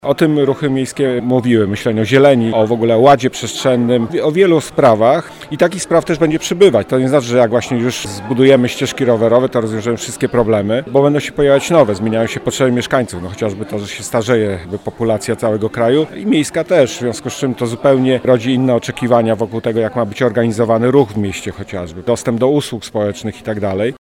Ruchy miejskie zawsze były punktem oporu wobec proponowanej polityki – mówi socjolog Edwin Bendyk: